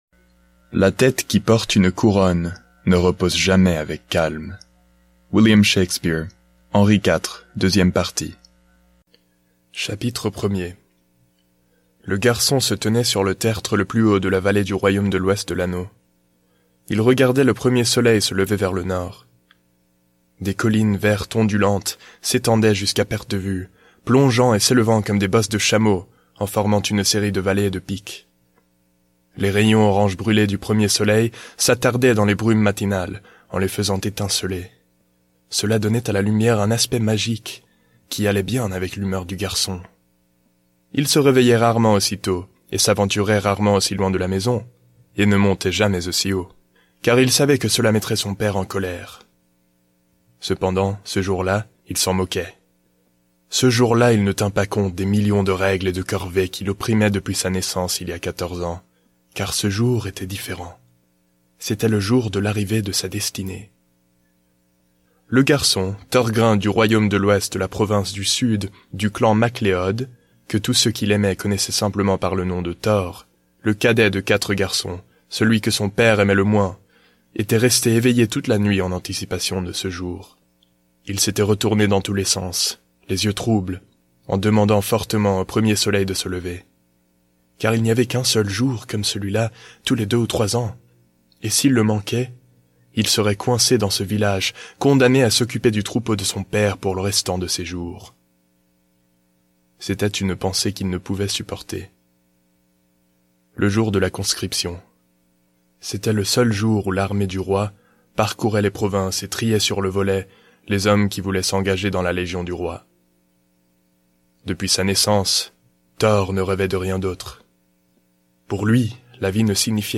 Аудиокнига La Quête Des Héros | Библиотека аудиокниг